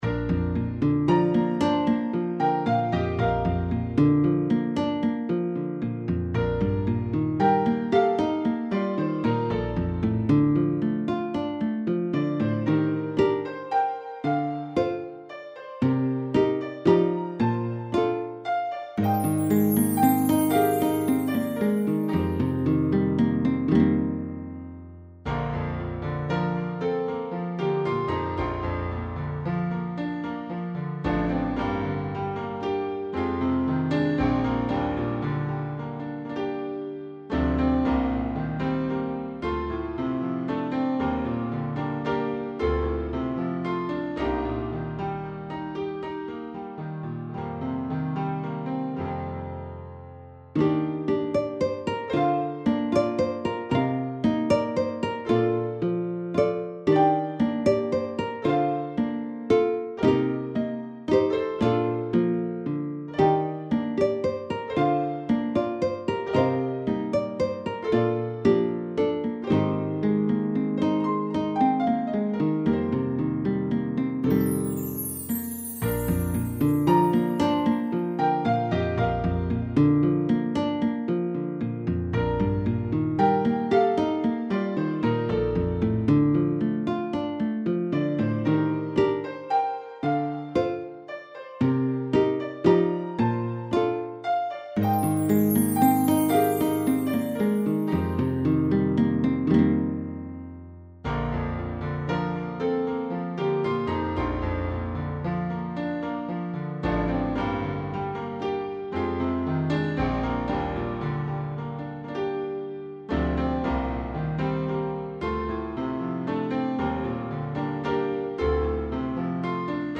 BGM
ロング明るい穏やか